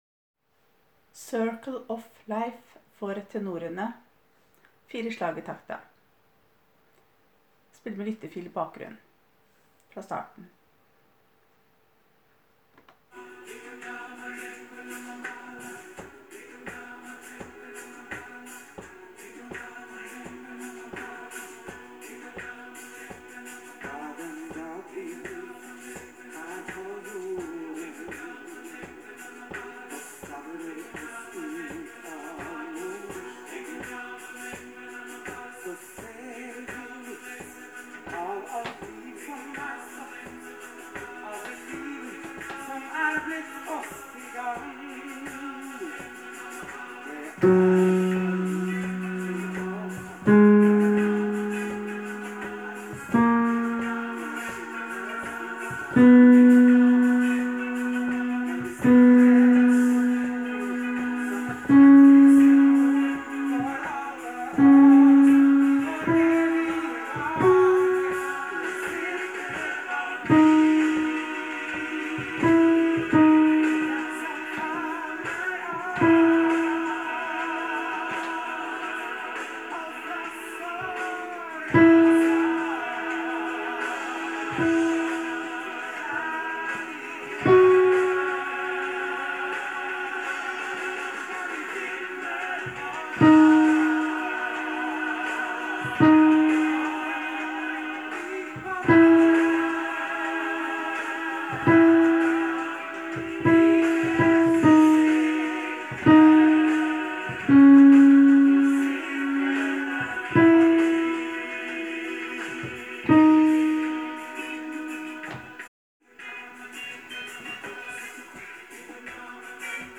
Haldens største blanda kor!
Circle of life – 2. alt (tenor) – med lyttefil i bakgrunnen: